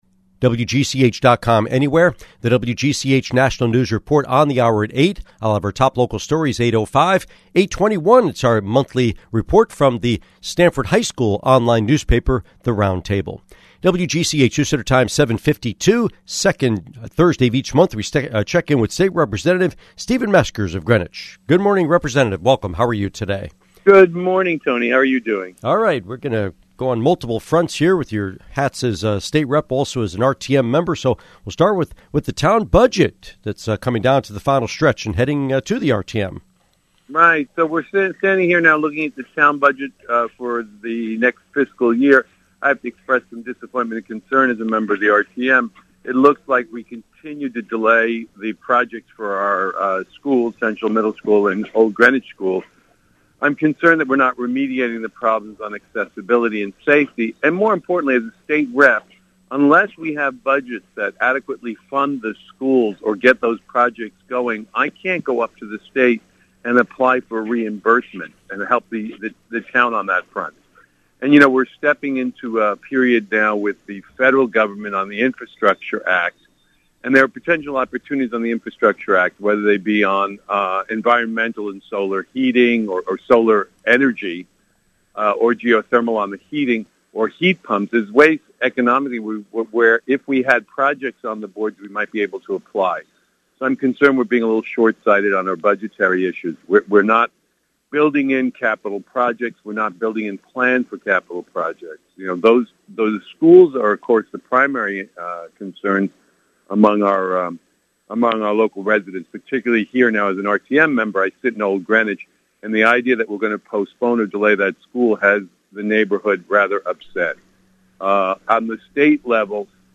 Interview with State Representative Stephen Meskers